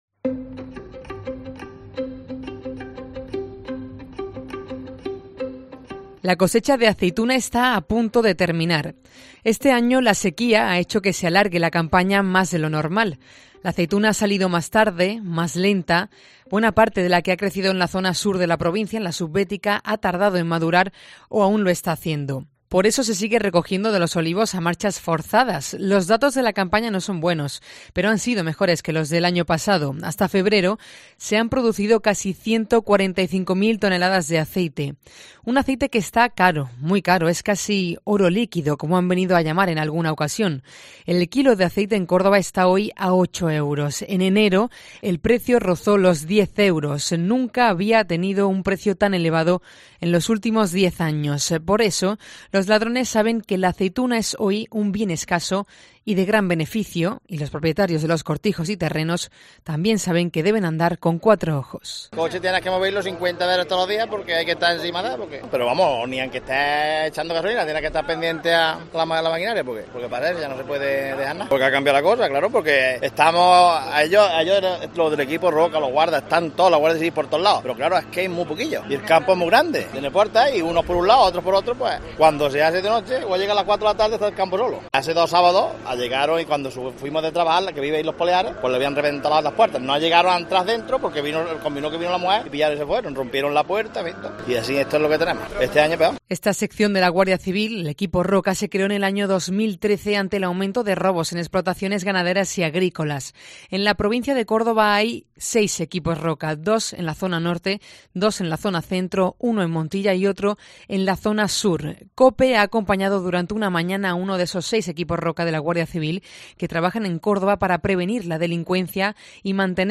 REPORTAJE
COPE acompaña a uno de los seis Equipos ROCA de la Guardia Civil en la provincia de Córdoba